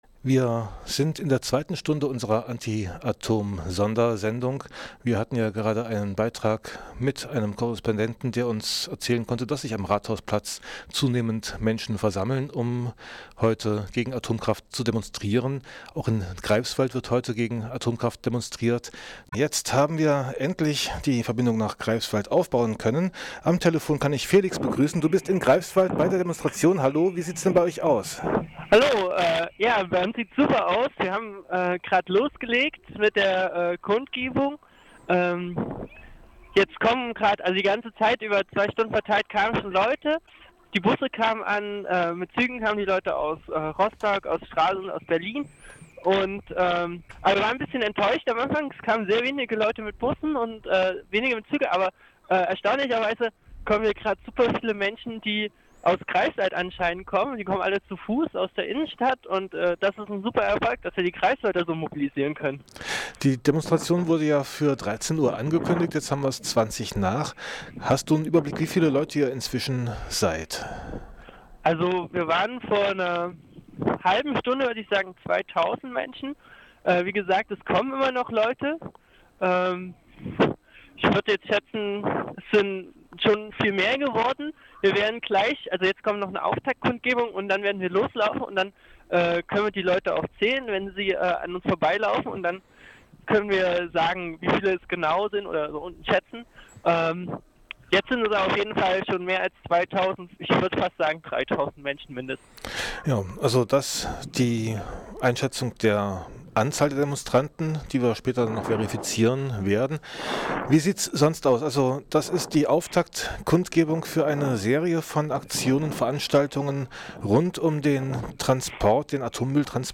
Erste Eindrücke von der Demo in Greifswald am 11.12.2010